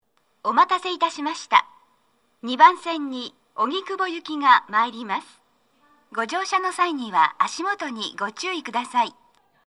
鳴動は、やや遅めです。
女声
接近放送1